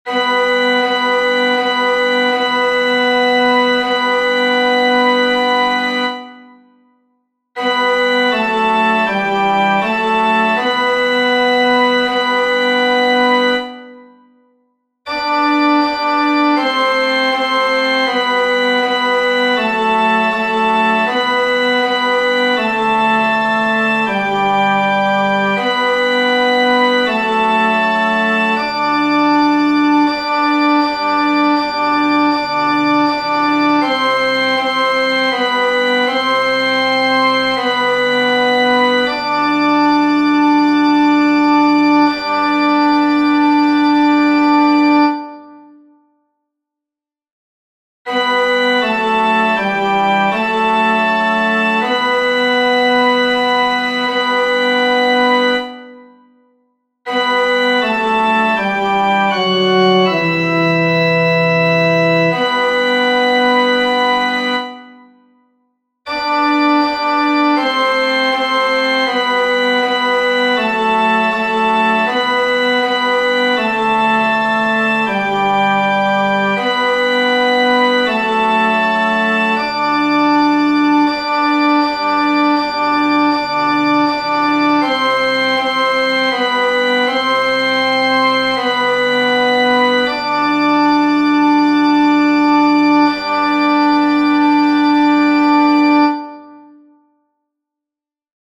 FF:VH_15b Collegium musicum - mužský sbor, FF:HV_15b Collegium musicum - mužský sbor
Laska_opravdiva-T2.mp3